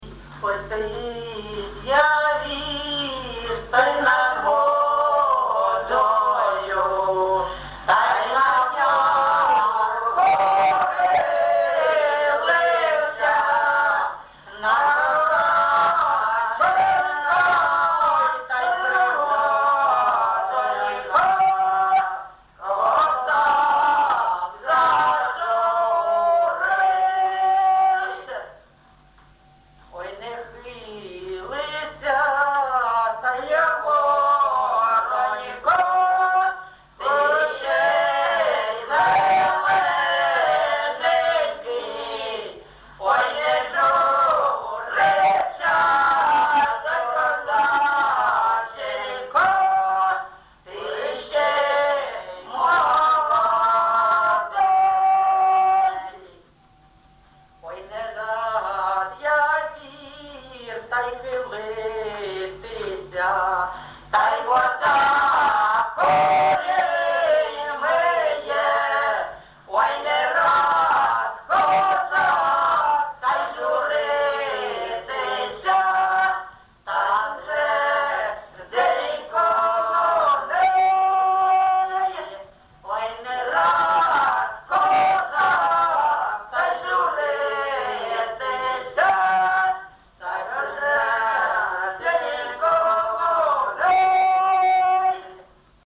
ЖанрПісні з особистого та родинного життя
МотивНещаслива доля, Журба, туга
Місце записум. Єнакієве, Горлівський район, Донецька обл., Україна, Слобожанщина